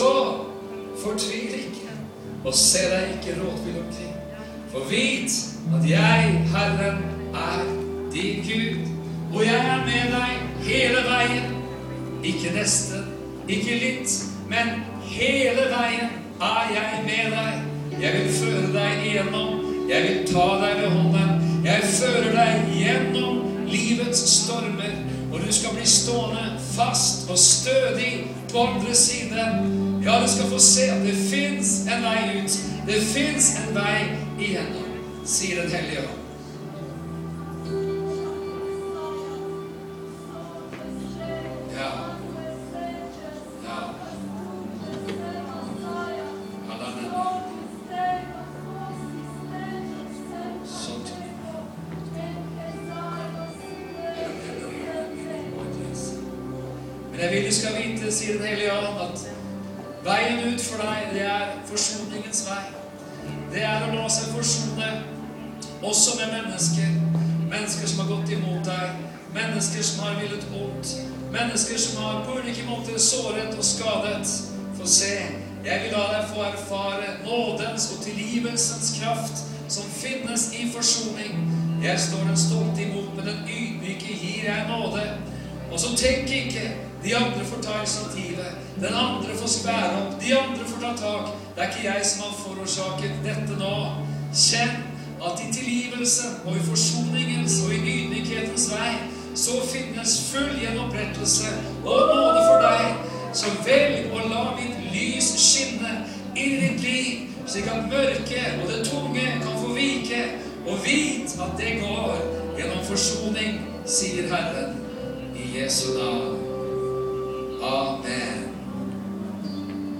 Frelst inn i Jesu kyrkje. Forsoninga sin veg. Møte i Jesus Church 1.12.2013.